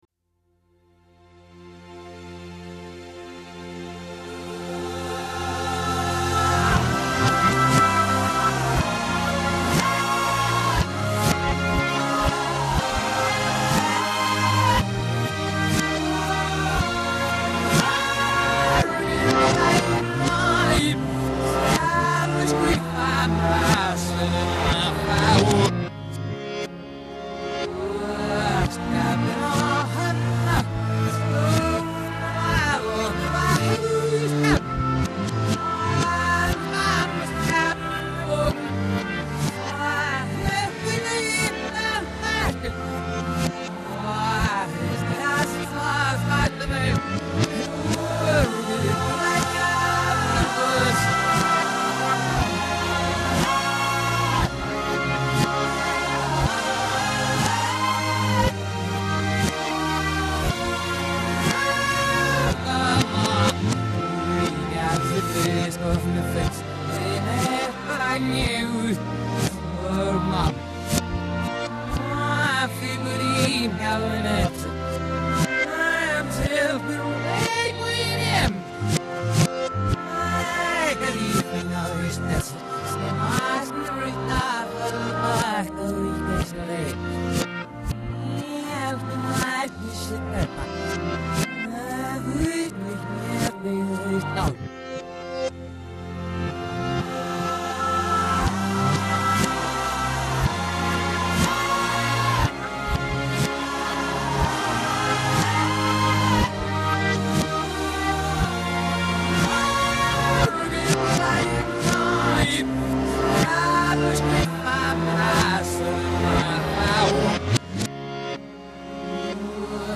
wonderful emotional backing vocals
unique wailing